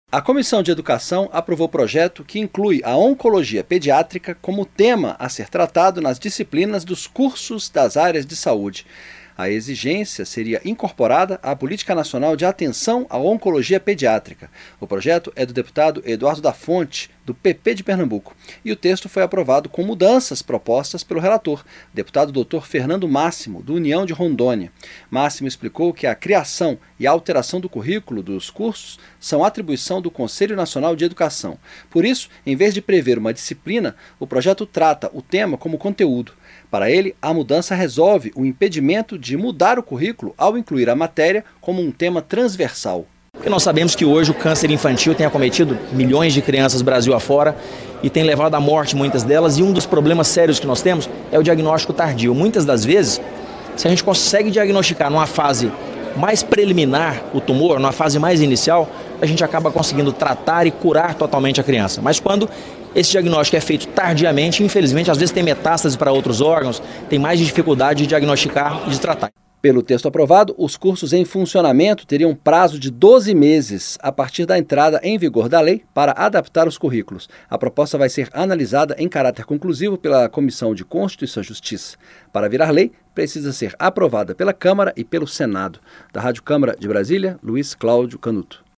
Projeto em discussão na Câmara inclui tratamento de câncer infantil como conteúdo de cursos de saúde - Radioagência